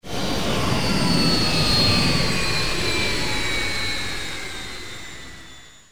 PowerDown.wav